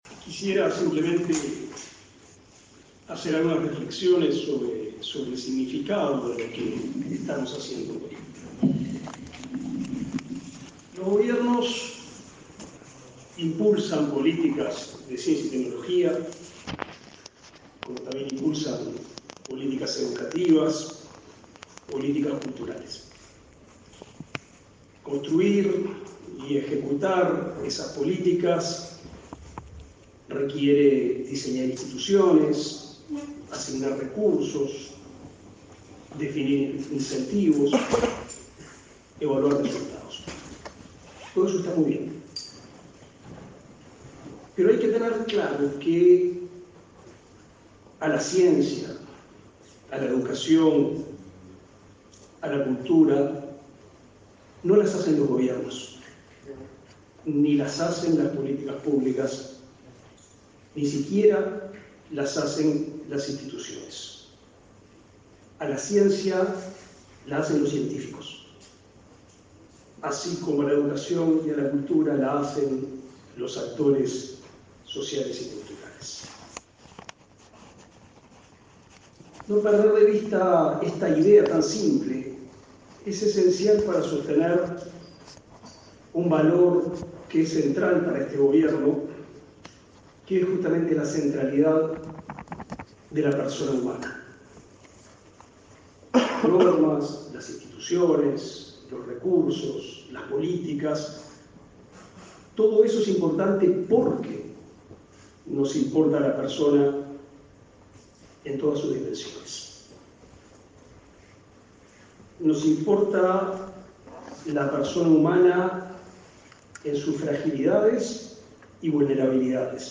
Palabras del ministro de Educación y Cultura y del científico Rafael Radi
El ministro de Educación y Cultura, Pablo da Silveira, encabezó este jueves 23 el acto de entrega del Gran Premio Nacional de Ciencias 2021, que